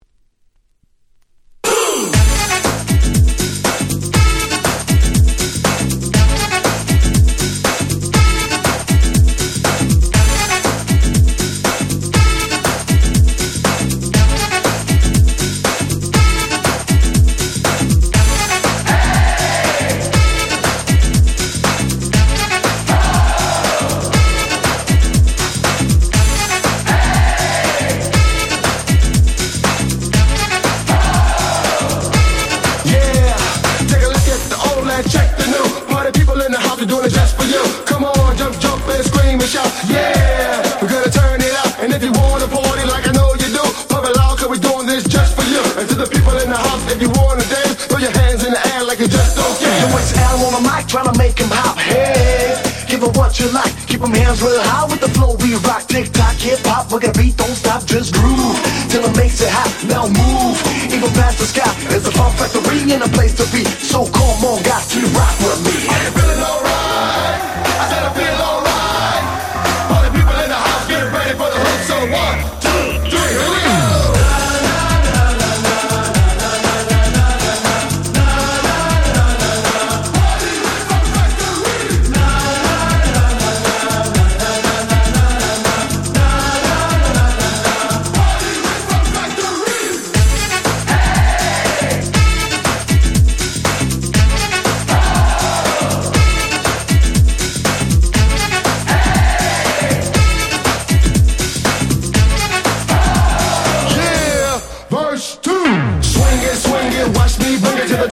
97' Smash Hit Dance Pop !!